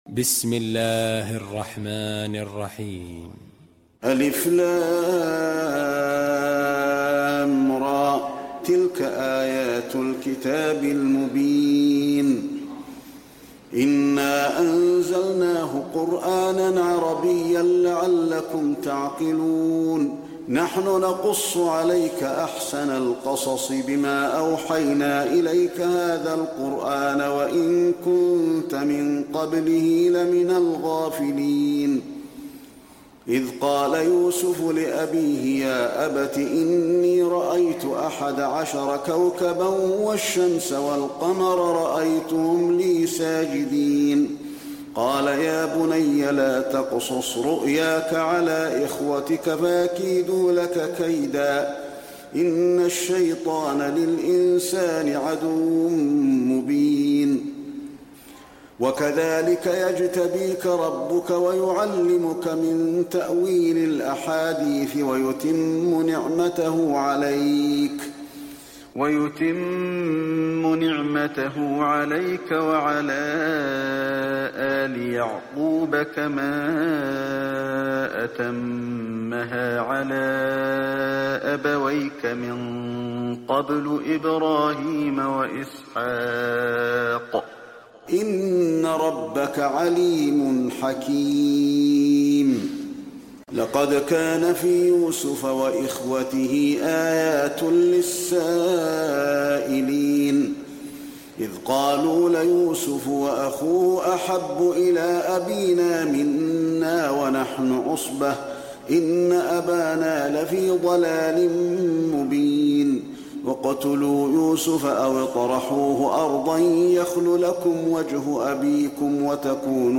المكان: المسجد النبوي يوسف